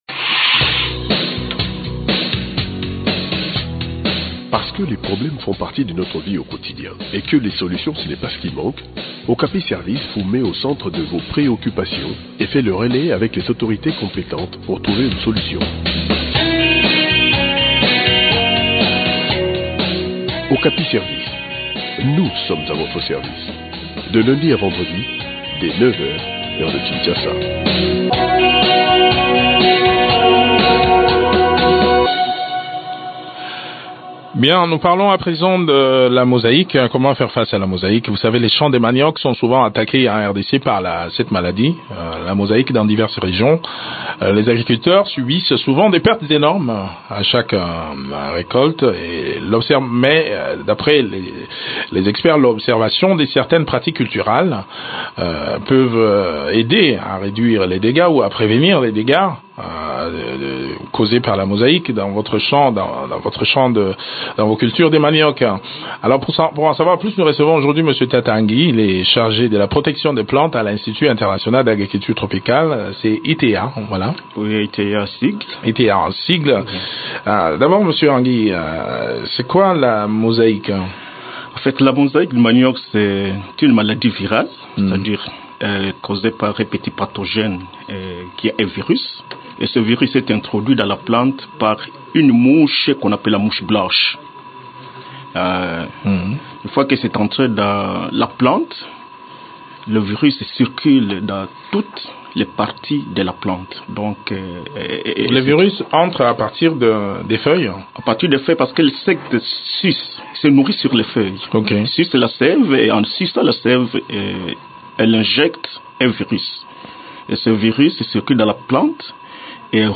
Suivez les recommandations qui peuvent vous aider dans cette interview